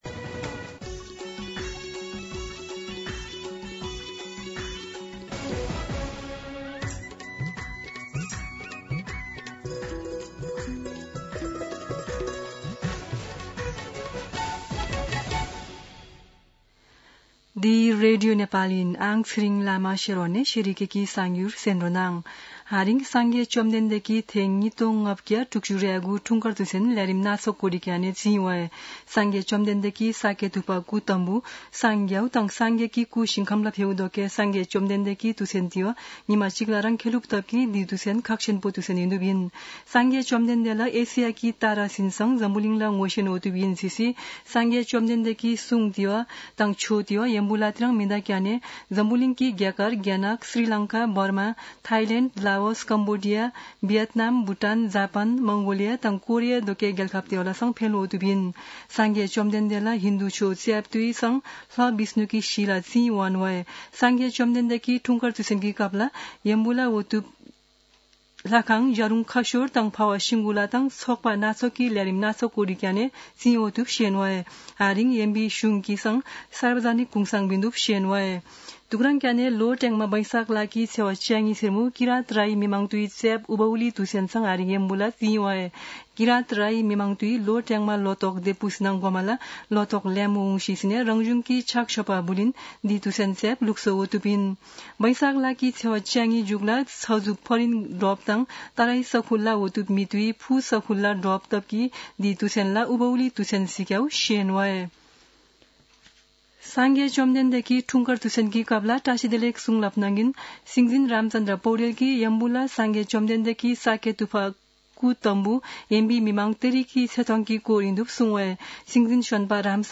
An online outlet of Nepal's national radio broadcaster
शेर्पा भाषाको समाचार : २९ वैशाख , २०८२